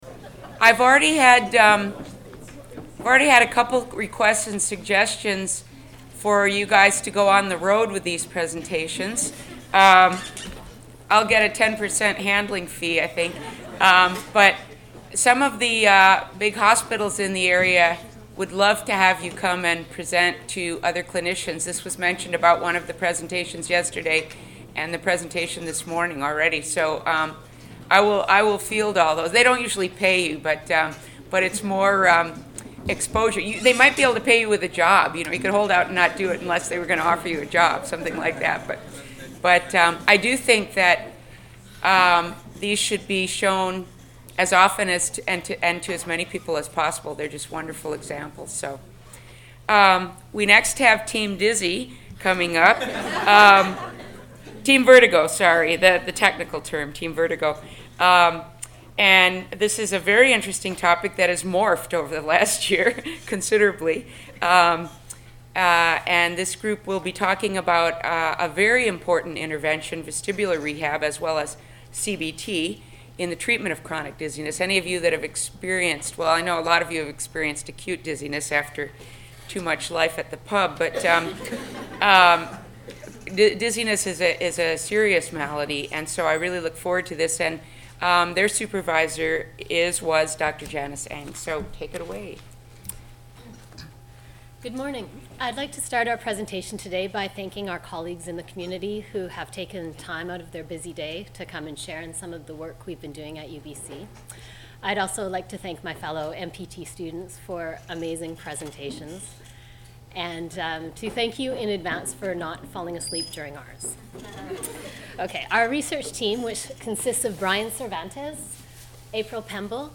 Systematic Review Presentations - Master of Physical Therapy Graduating Students, University of British Columbia - 2006, Presented on September 14-15, 2006 , Vancouver, BC, Canada.